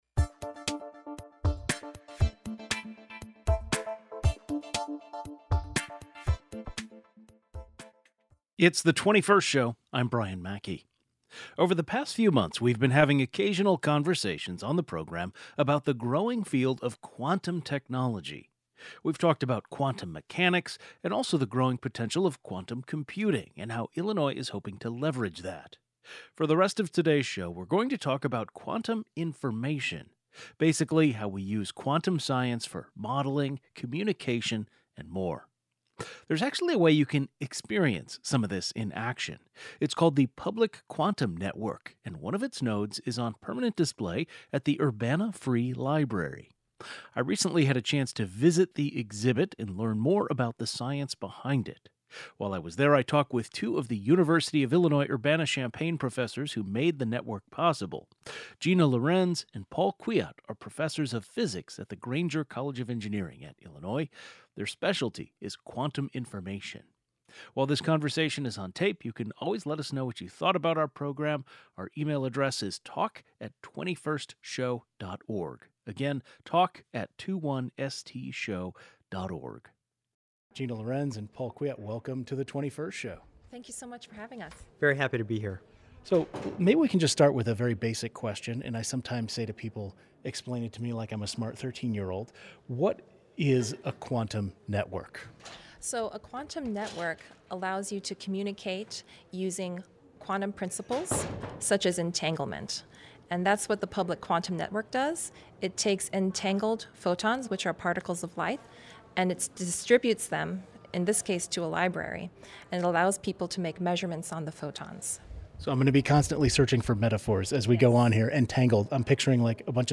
What does quantum technology look like in action? We went to the library to see first-hand